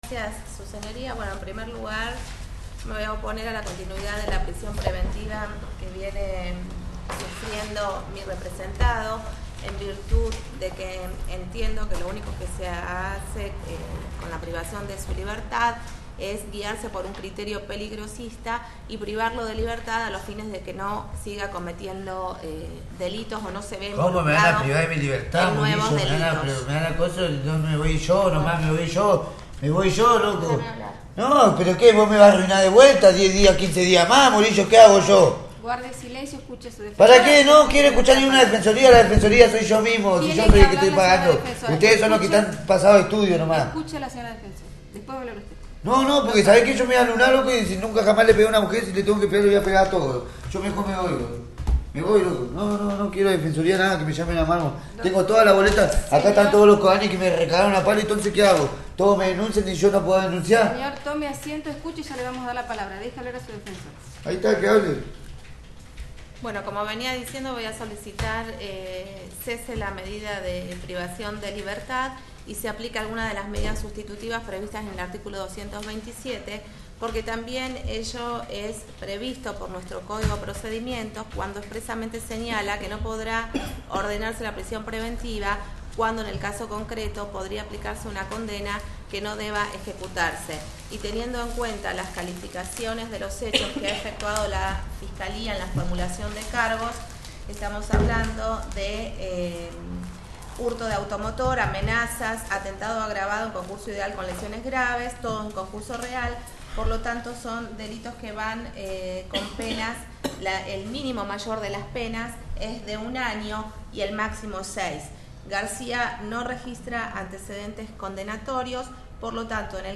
03_-_DEFENDSA_EXPONE.mp3